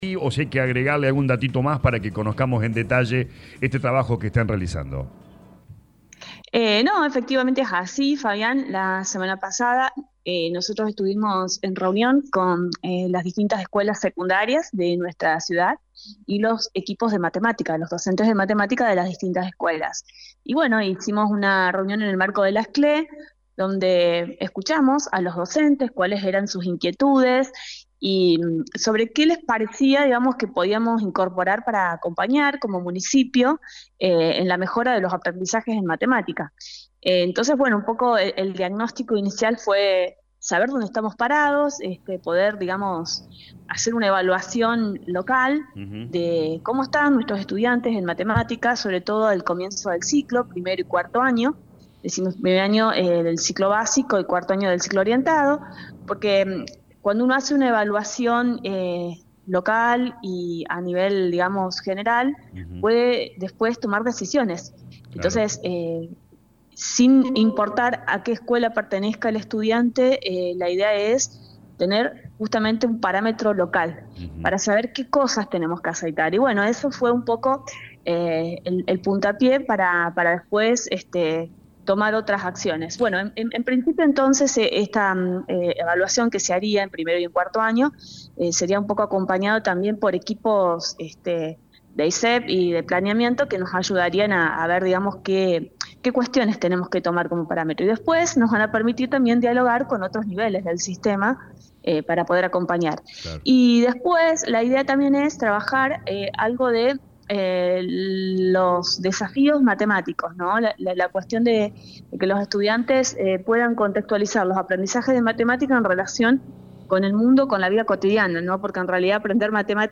Los detalles por explicó la Secretaria de Educación Municipal Lic. Marisol Nuñez en dialogo con LA RADIO 102.9.